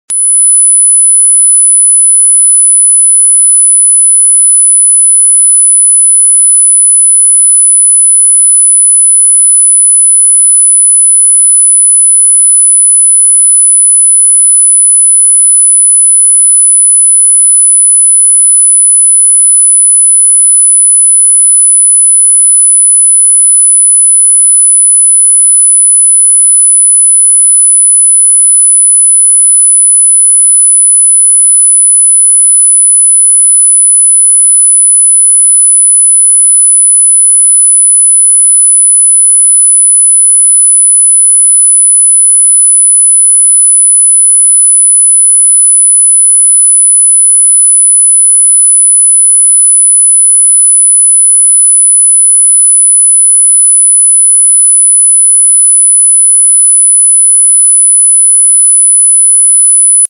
⚡ Title (for 9999Hz Frequency): sound effects free download
⚡ Title (for 9999Hz Frequency): Tap Into Higher Energy ⚡ | 9999Hz Ultra-Frequency Awakening